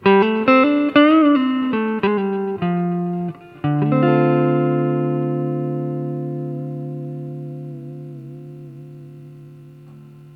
Записано все на советский конденсаторный микрофон (или в линию).
с эквалайзером